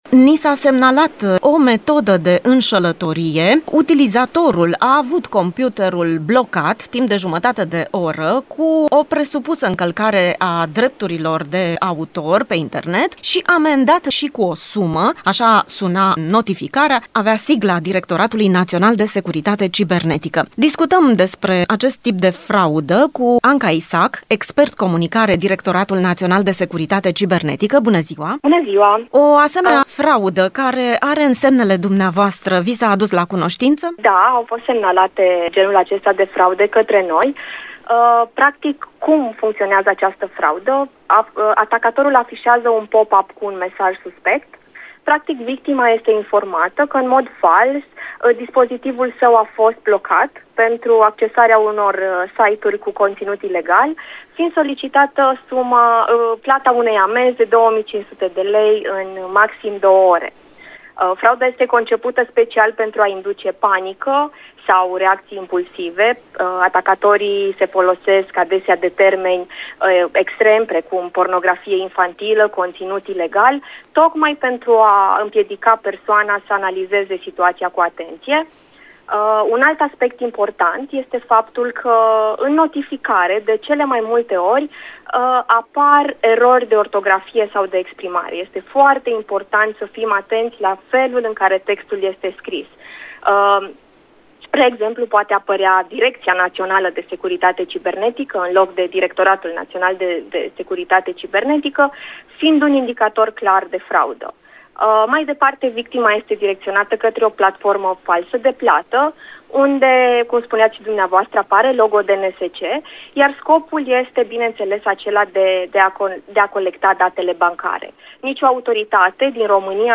Discuția pornește de la unul din mesajele înșelătoare care circulă, având chiar sigla Directoratului.